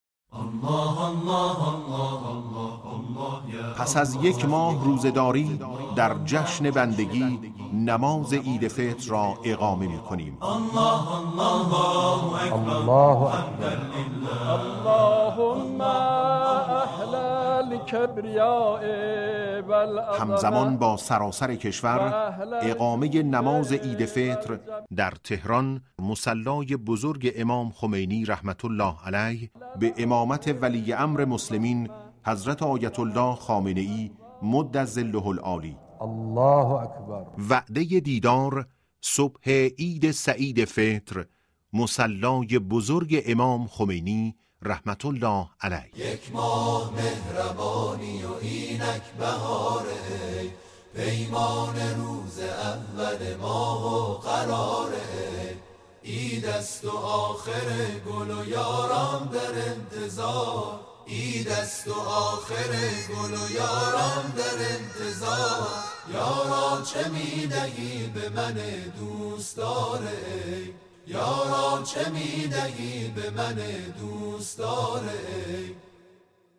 نماز و خطبه‌های عید سعید فطر همزمان با عید سعید فطر به امامت رهبر معظم انقلاب اسلامی برگزار و از رادیو معارف به‌صورت زنده پخش می‌شود.